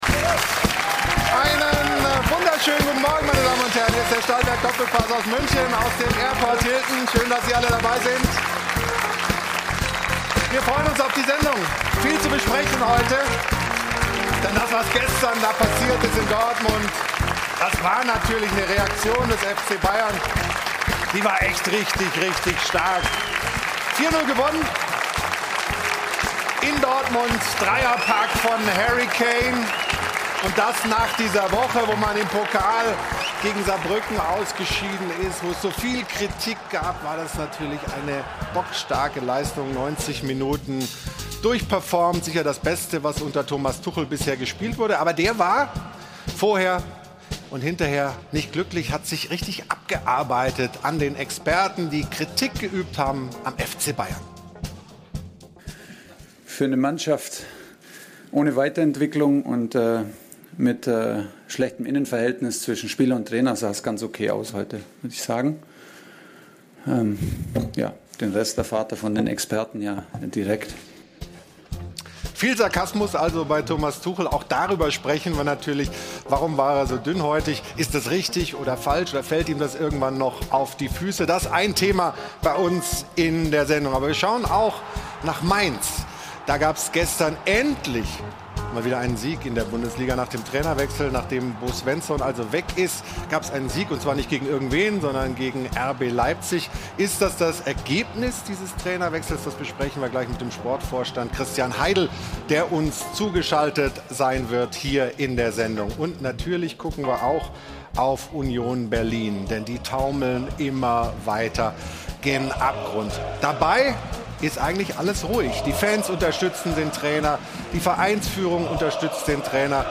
Außerdem zugeschaltet: Christian Heidel, der Sportvorstand von Mainz 05.